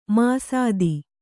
♪ māsādi